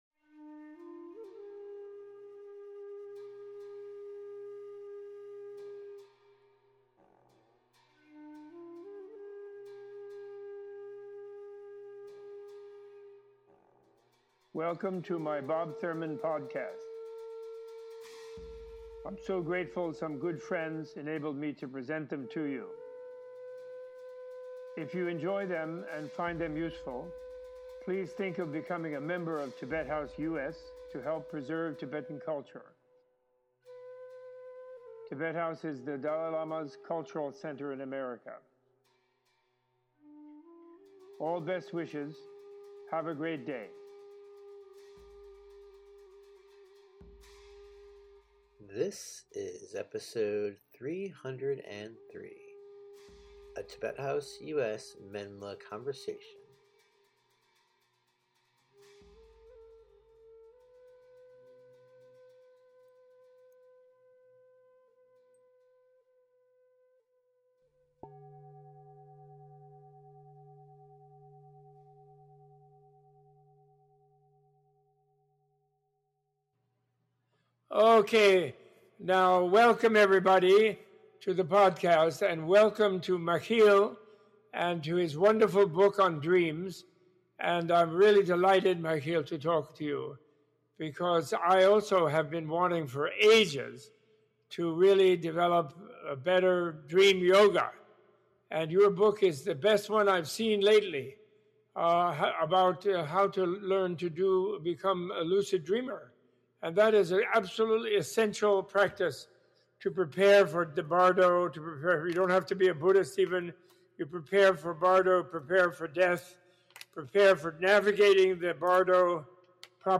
A Tibet House US | Menla Conversation